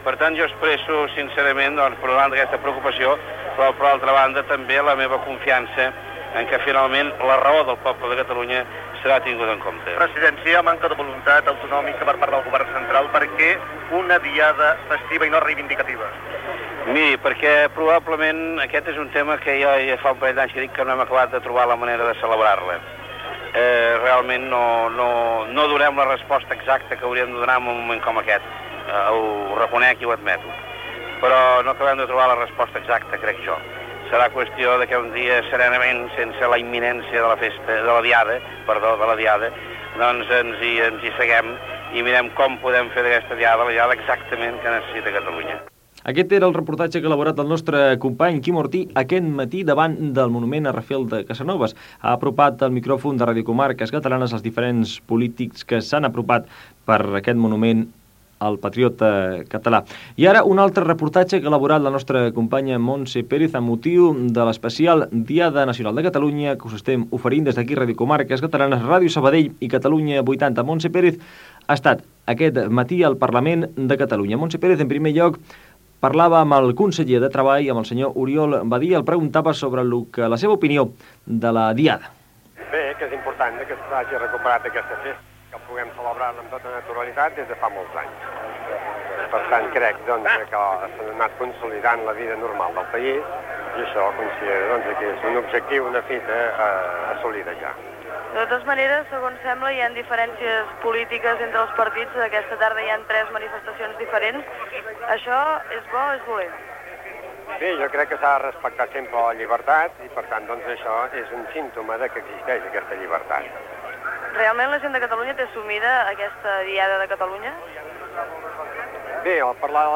Paraules del president de la Generalitat Jordi Pujol (enregistrades al monunent de Rafel Casanova de Barcelona) i d'Oriol Badia, conseller de treball la Generalitat (enregistrades al Parlament de Catalunya).
Informatiu